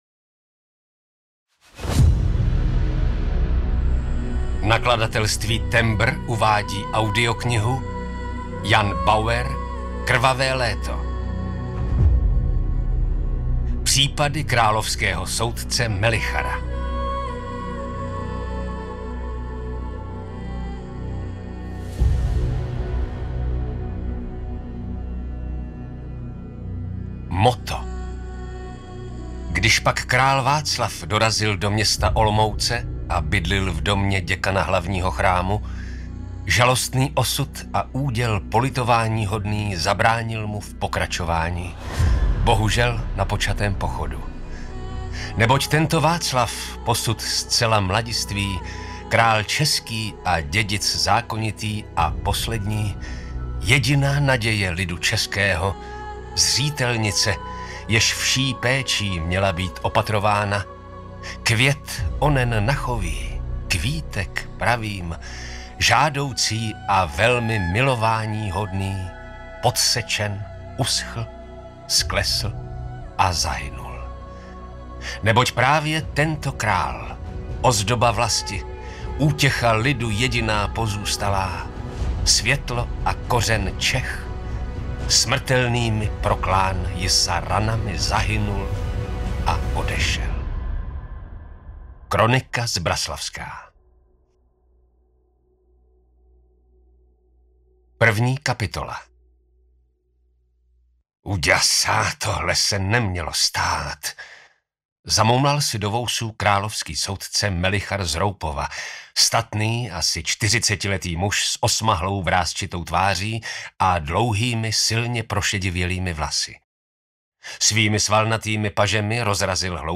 Krvavé léto audiokniha
Ukázka z knihy
krvave-leto-audiokniha